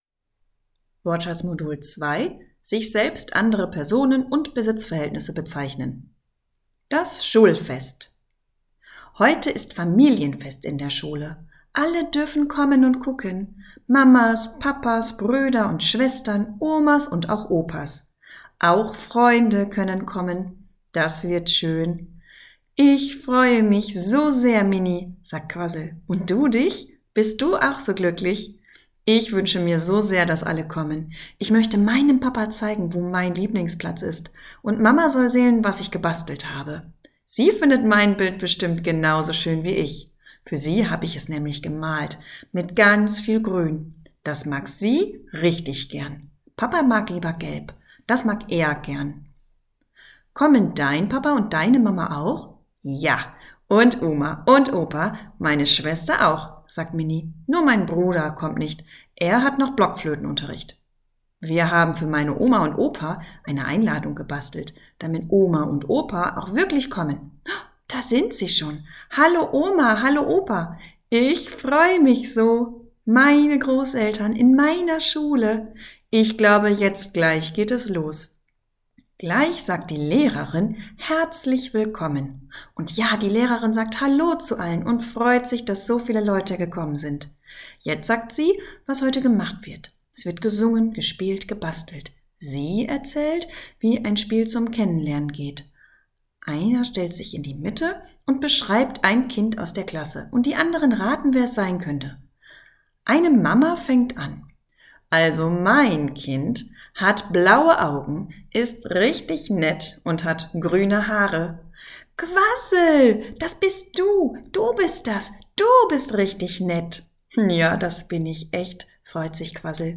Die Aufnahmen sind mit viel Intonation und wörtlicher Rede eingesprochen, damit die Kinder ein lebhaftes Hörerlebnis haben.